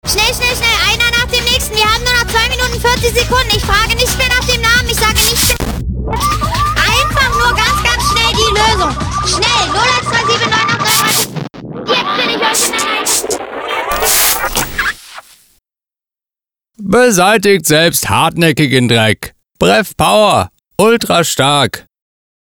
Nicht ganz sauber. Funkspots für BREF.